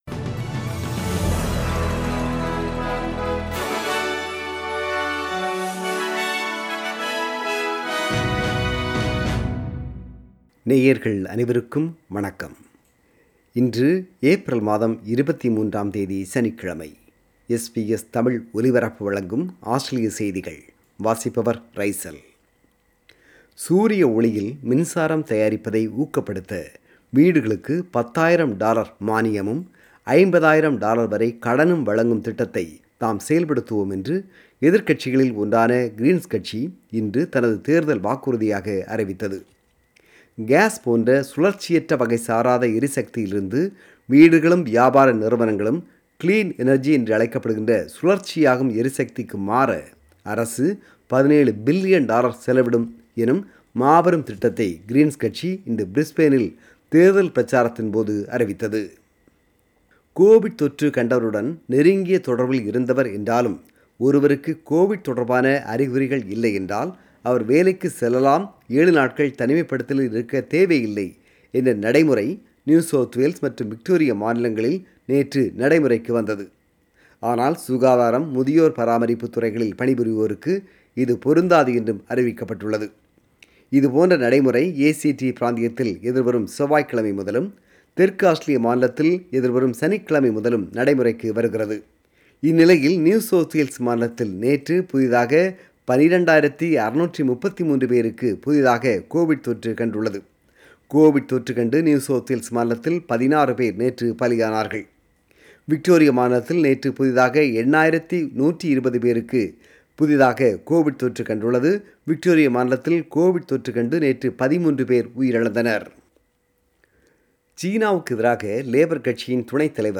Australian News